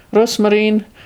Rosmarin ‘romarin’ (Tyrolien)